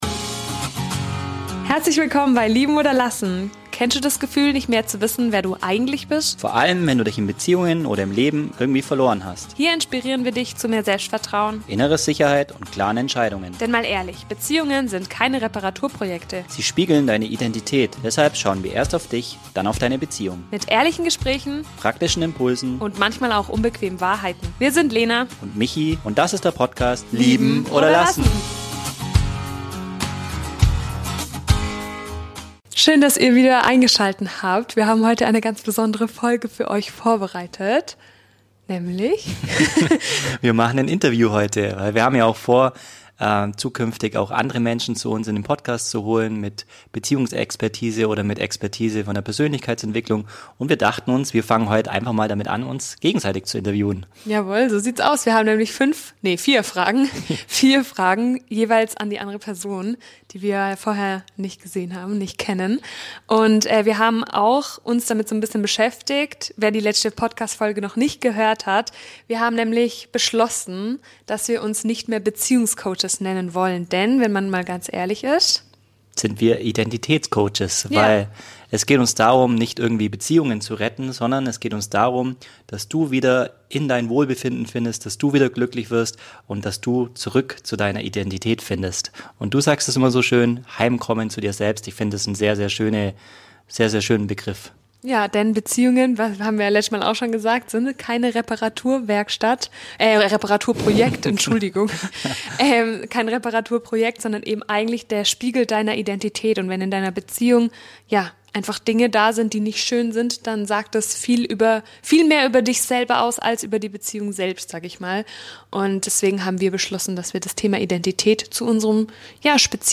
Eine ganz neue Folge voller überraschender Erkenntnisse über Identität, Beziehungen und Persönlichkeitsentwicklung. In dieser besonderen Episode stellen wir uns gegenseitig ungeplante Fragen - ohne Vorbereitung, ohne Skript.